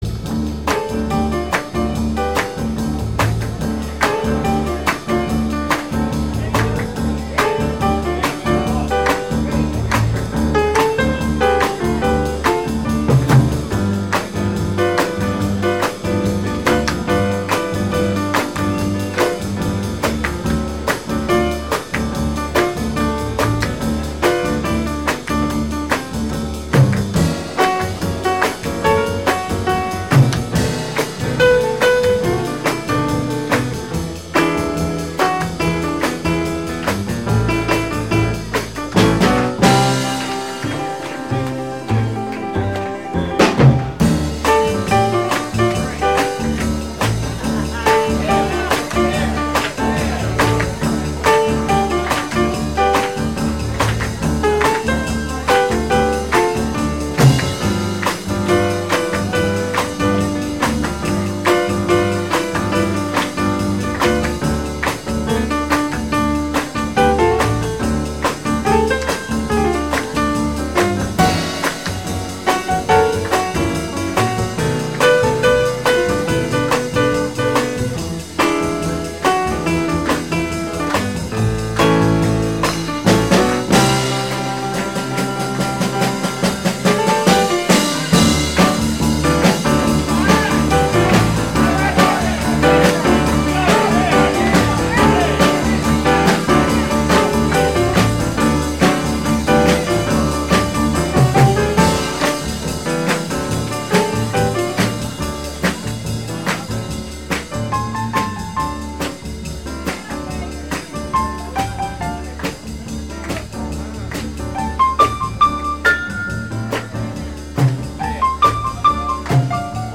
Затем ушел на «легкие хлеба», стал играть джаз-поп.